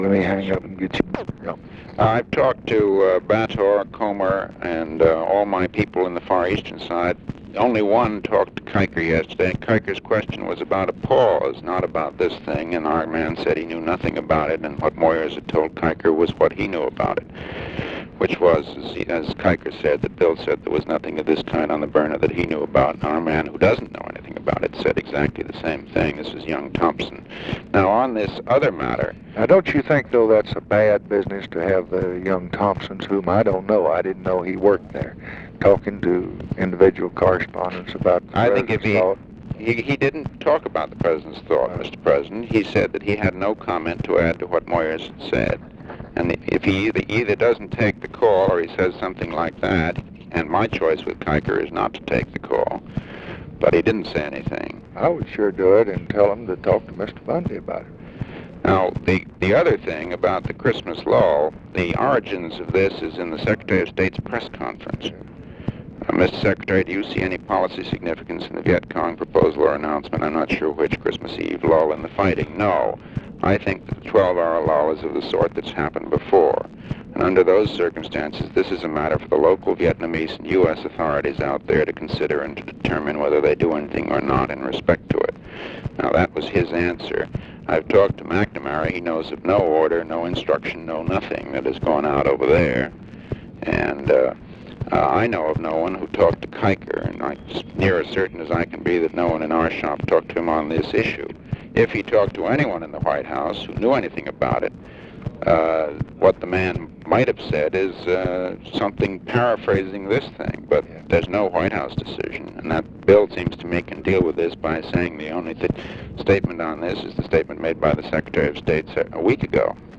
Conversation with MCGEORGE BUNDY, December 16, 1965
Secret White House Tapes